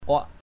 /ʊaʔ/